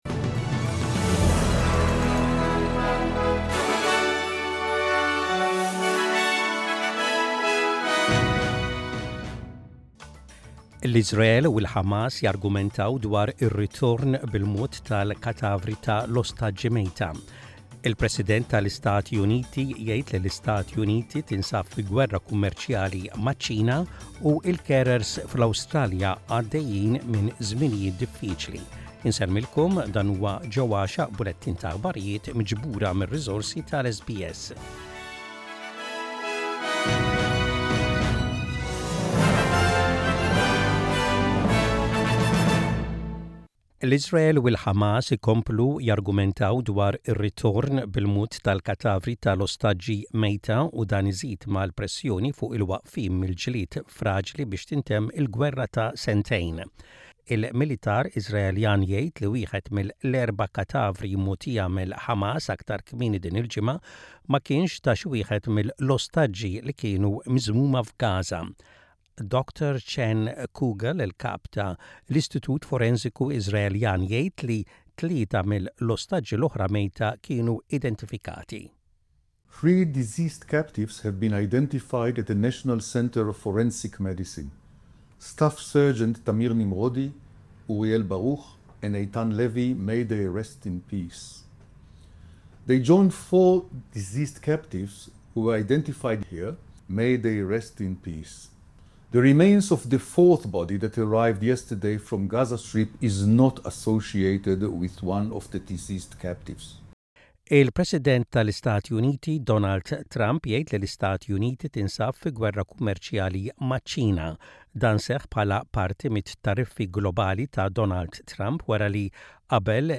Maltese News - Photo SBS Maltese